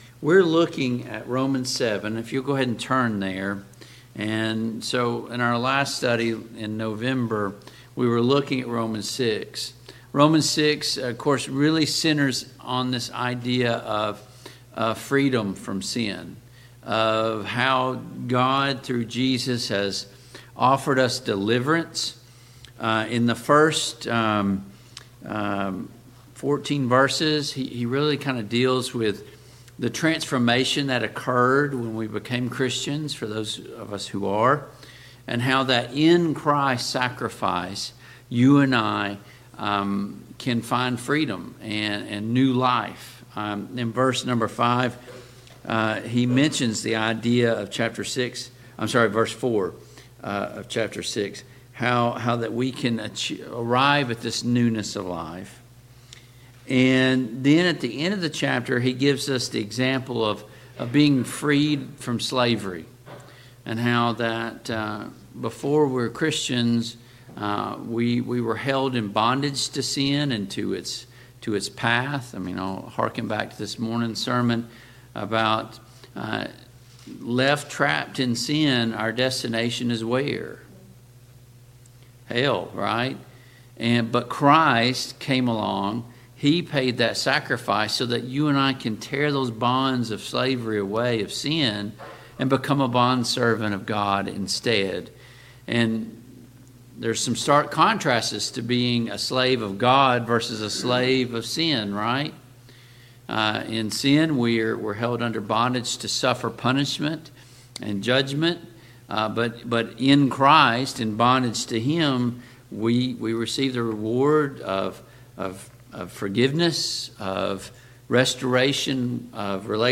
Romans 7:1-25 Service Type: PM Worship Topics: Divorce and Remarriage , The Law of Christ , The Old Law « 4.